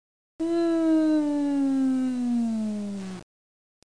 fire.mp3